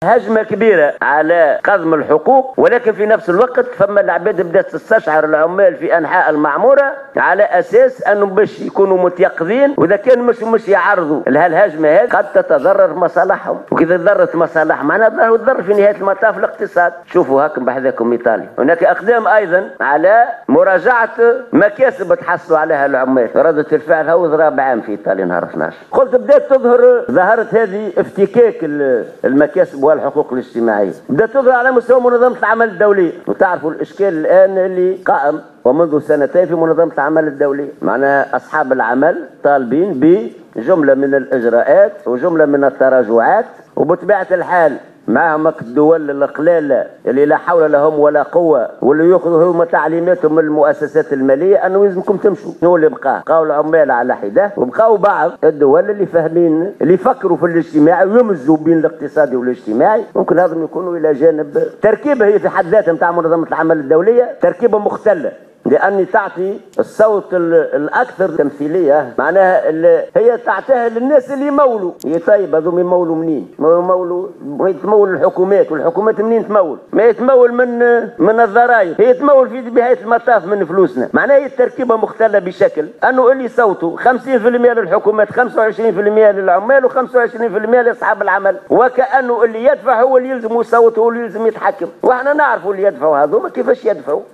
واكد العباسي في حوار مباشر، السبت، مع رئيسة الاتحاد التونسي للصناعة والتجارة والصناعات التقليدية وداد بوشماوي، جرى في اطار فعاليات الدورة 29 لايام المؤسسة بسوسة حول محور الحوار الاجتماعي والمرونة في العلاقة الشغلية، ان المنظمة الشغيلة على استعداد دائم لمواصلة الحوار مع اصحاب المؤسسات بشان عناصر المرونة، التي افرزتها المراجعات الاخيرة لمجلة الشغل.